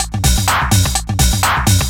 DS 126-BPM A05.wav